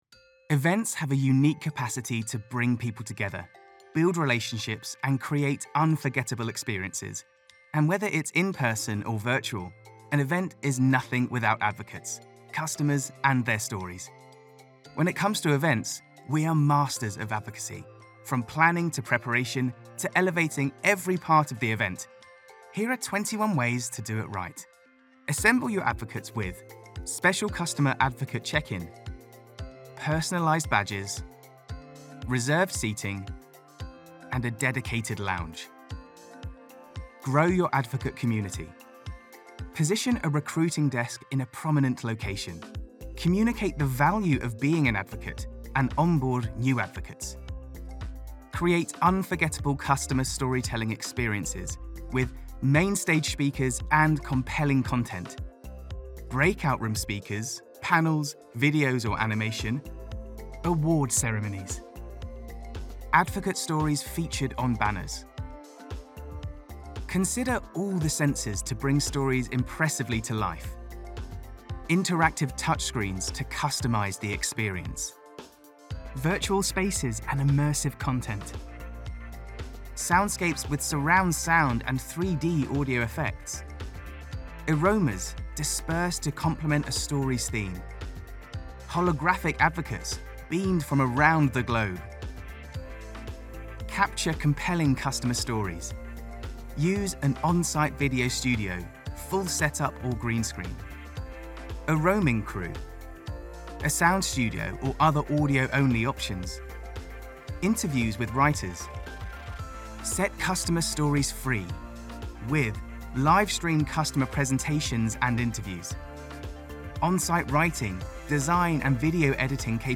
Inglés (Británico)
Comercial, Joven, Natural, Travieso, Amable
Corporativo